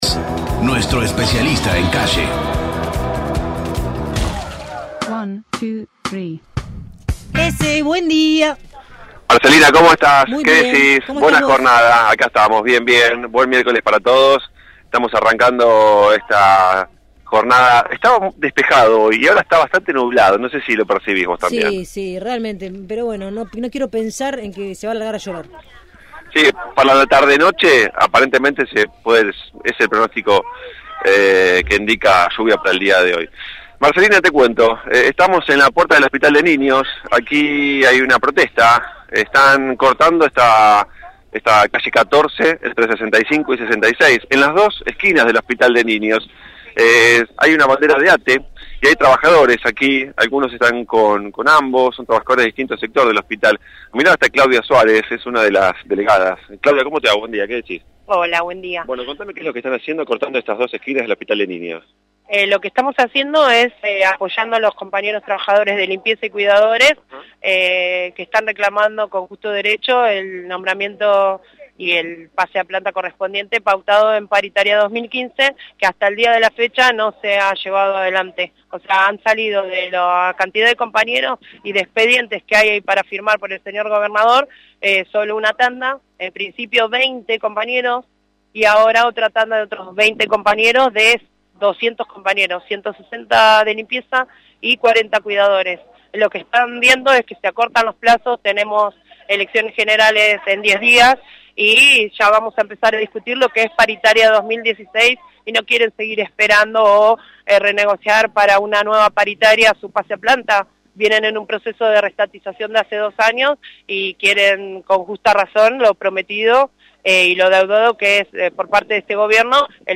MÓVIL/ Reclamo de trabajadores del Hospital de Niños